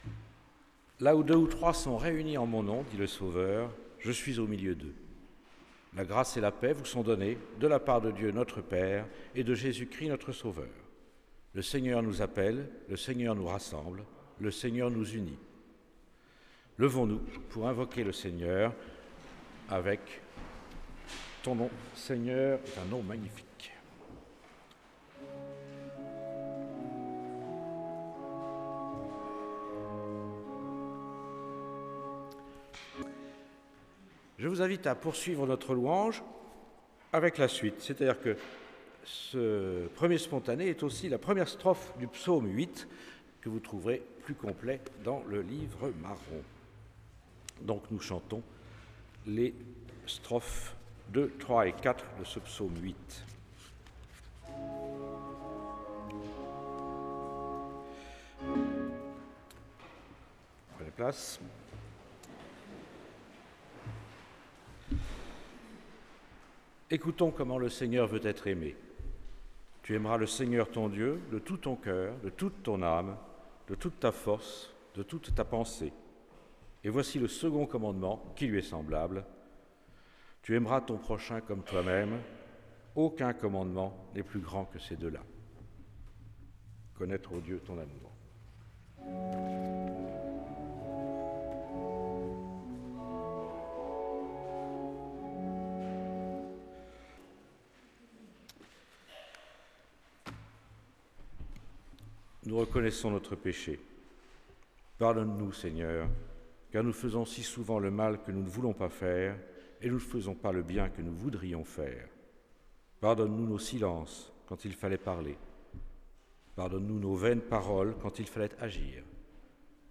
Culte du 7 juin 2015